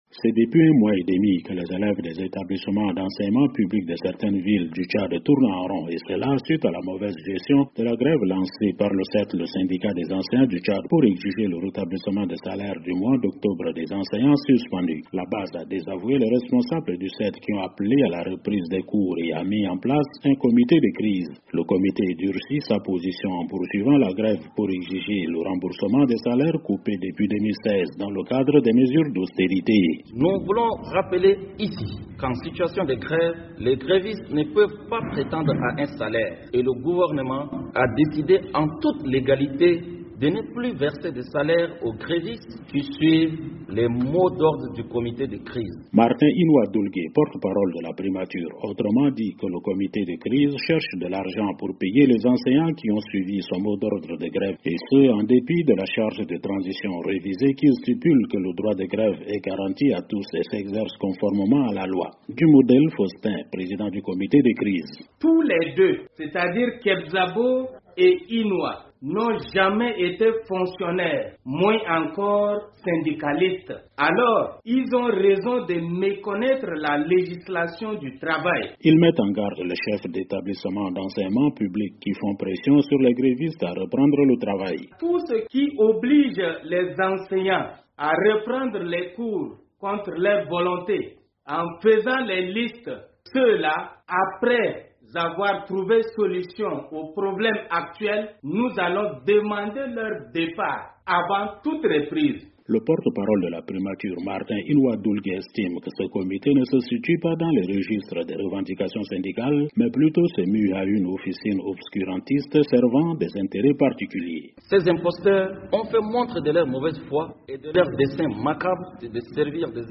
depuis N'Djamena.